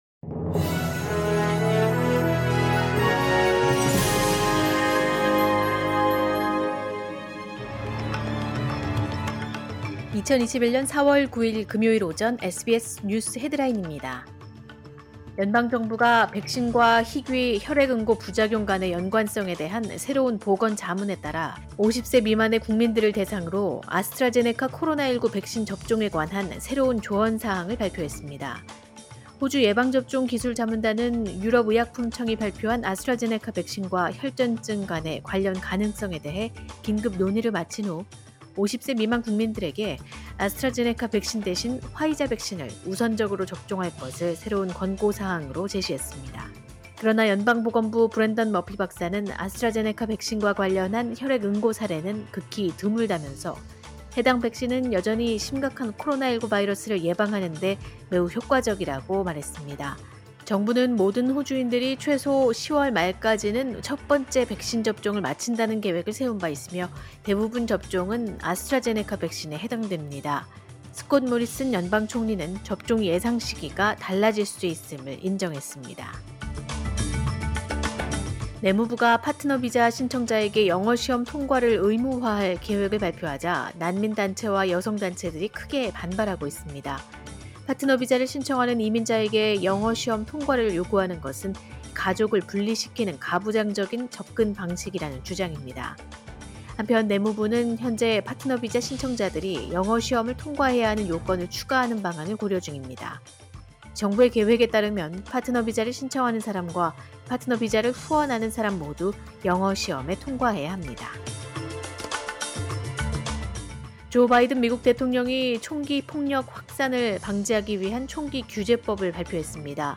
2021년 4월 9일 금요일 오전의 SBS 뉴스 헤드라인입니다.